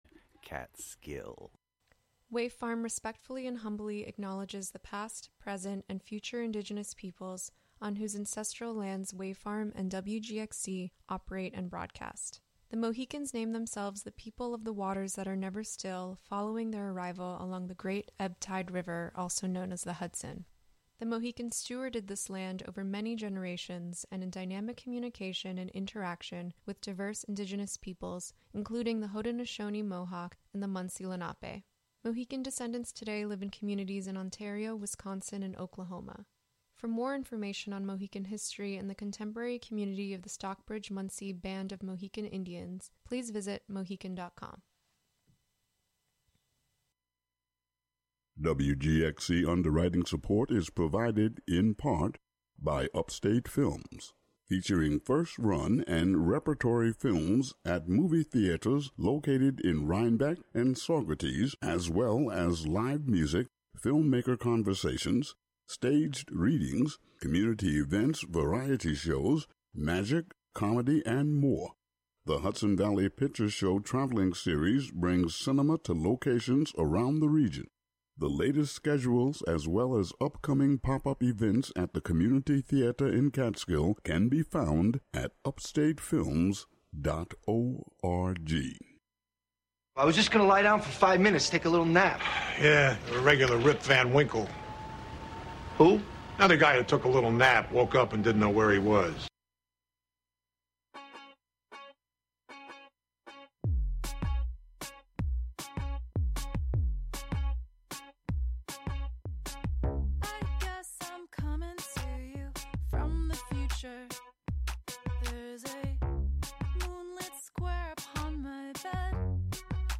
The Bound By Books crew discusses books they love, books they're currently reading, and other teen media and literary topics.